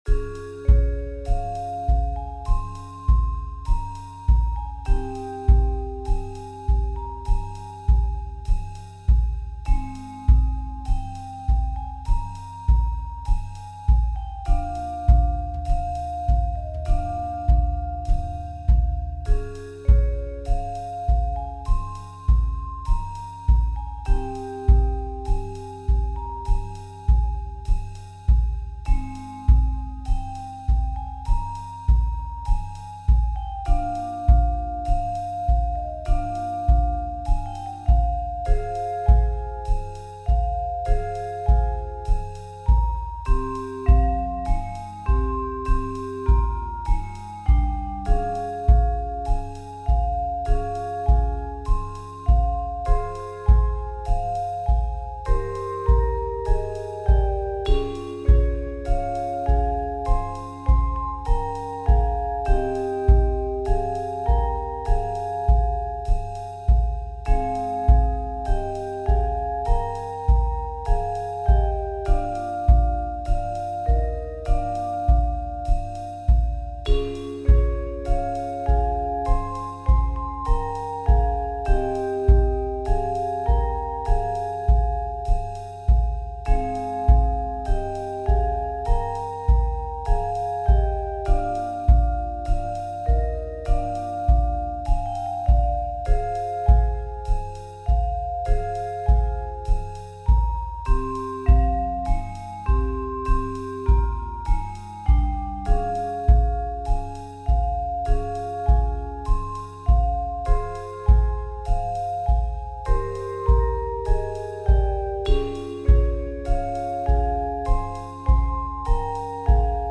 Why suffer when you can relax yourself to elevator music?
Puzzling_Under_Elevator_Music.mp3